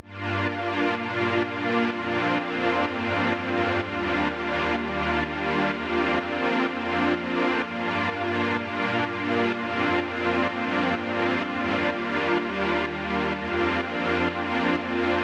描述：伴随着打击乐和hihats的加入的me。
标签： 126 bpm House Loops Drum Loops 2.56 MB wav Key : Unknown
声道立体声